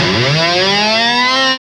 Index of /90_sSampleCDs/Roland LCDP02 Guitar and Bass/GTR_GTR FX/GTR_Gtr Hits 1